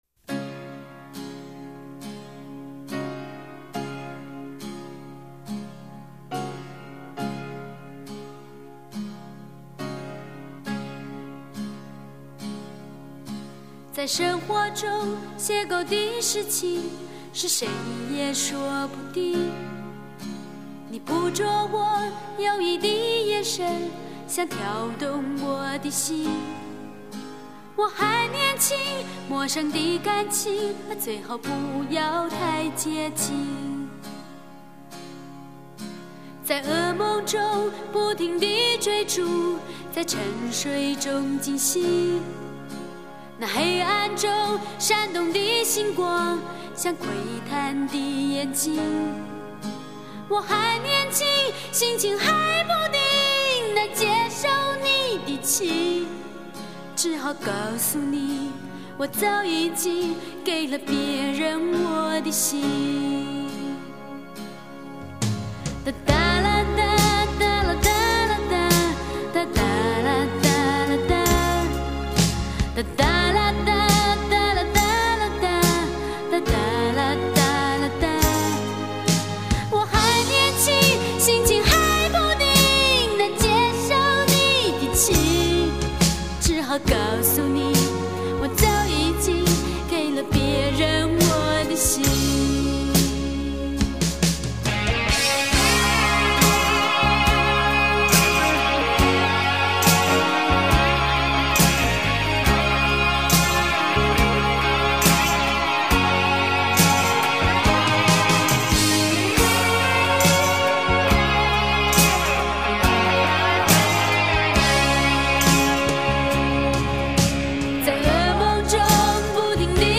纯情少女那种清澈般的歌声十分迷人。